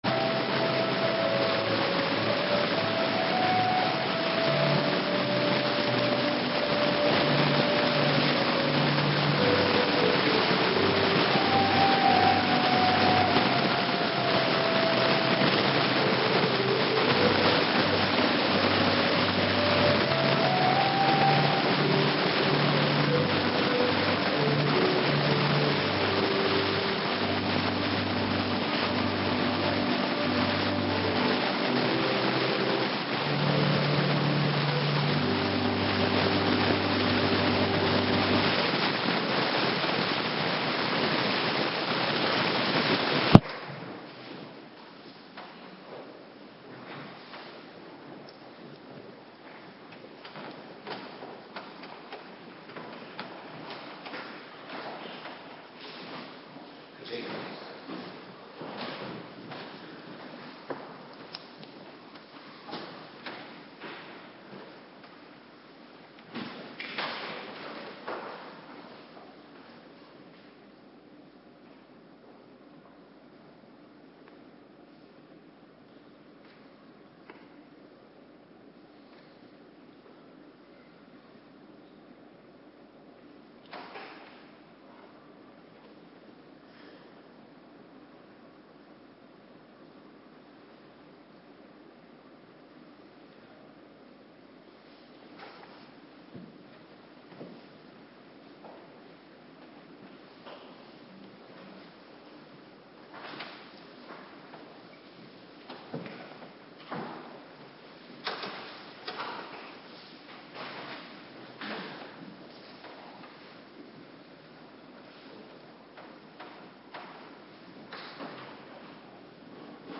Avonddienst Bijbellezing
Locatie: Hervormde Gemeente Waarder